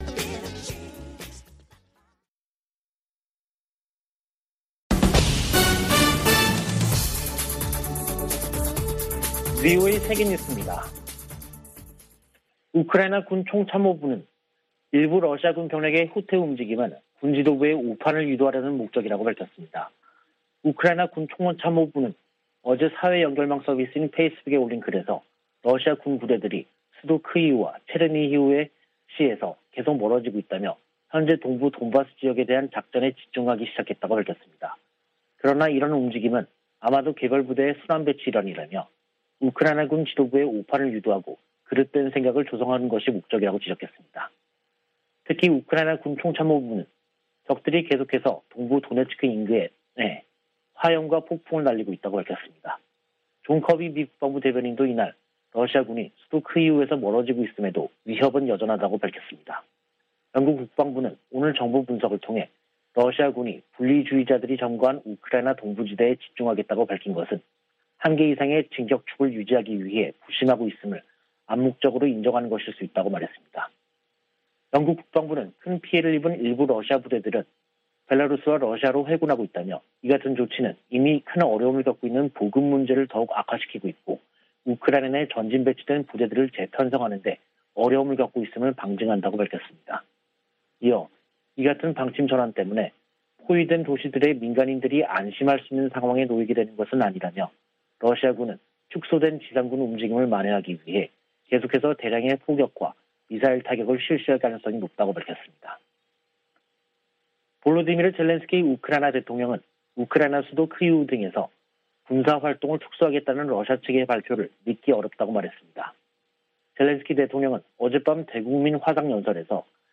VOA 한국어 간판 뉴스 프로그램 '뉴스 투데이', 2022년 3월 30일 3부 방송입니다. 조 바이든 미국 대통령과 리셴룽 싱가포르 총리가 북한의 잇따른 탄도미사일 발사를 규탄하고 대화로 복귀할 것을 촉구했습니다. 북한의 최근 ICBM 발사는 미사일 역량이 증대 됐음을 보여준다고 미 인도태평양사령부 측이 밝혔습니다. 미 국방부가 북한 등의 위협에 대비하는 내용이 담긴 새 회계연도 예산안을 공개했습니다.